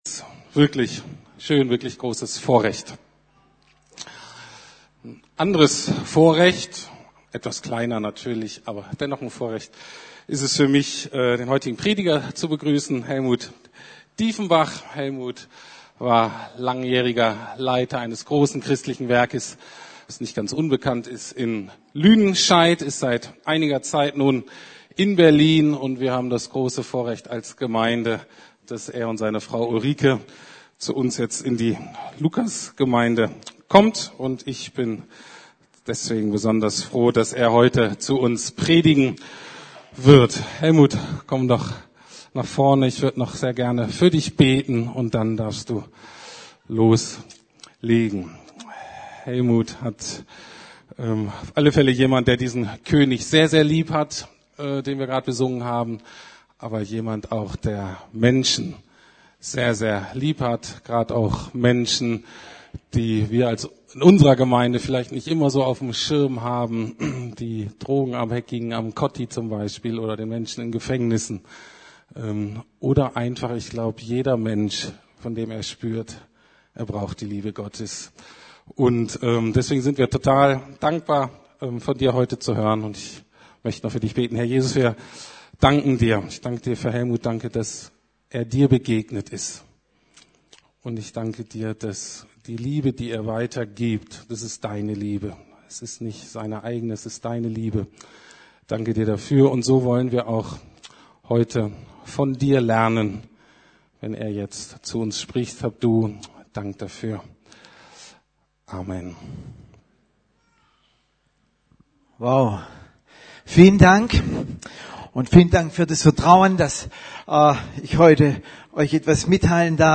Evangelisation und Barmherzigkeit ~ Predigten der LUKAS GEMEINDE Podcast